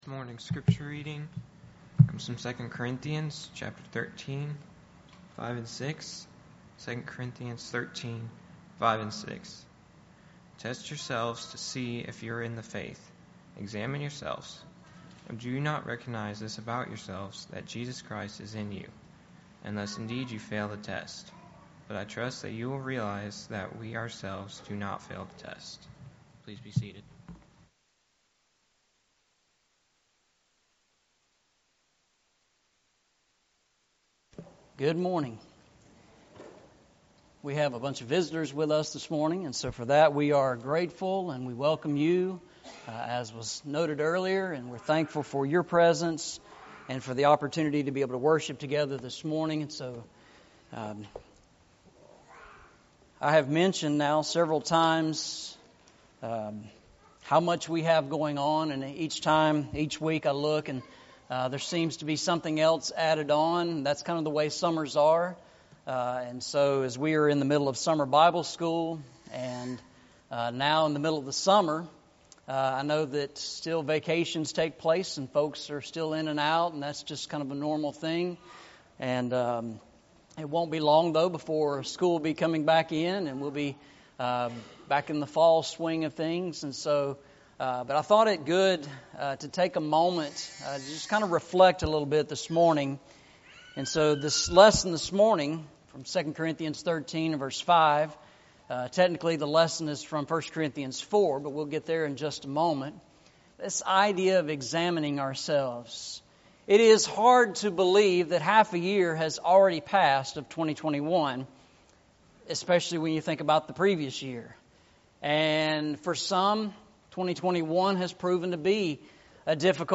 Eastside Sermons Passage: 2 Corinthians 13:5-6 Service Type: Sunday Morning « Walk Through the Bible